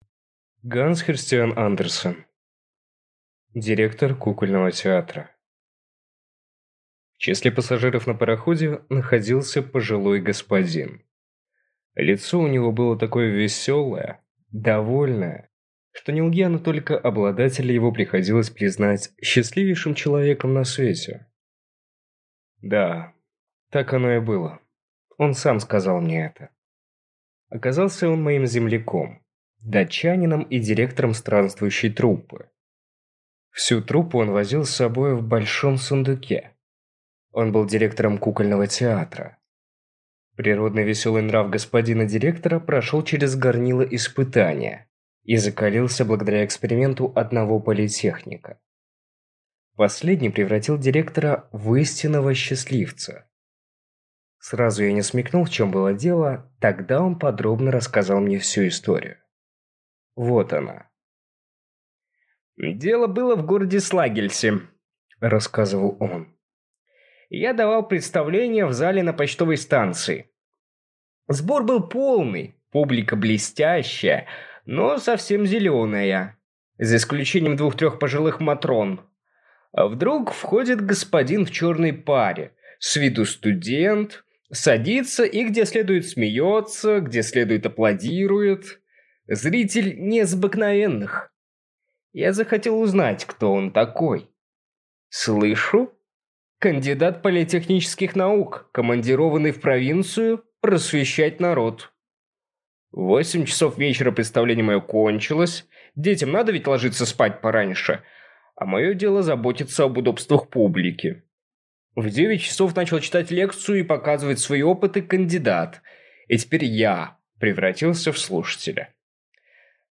Аудиокнига Директор кукольного театра | Библиотека аудиокниг